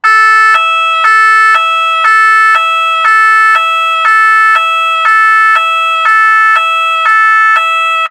Sons - Effets Sonores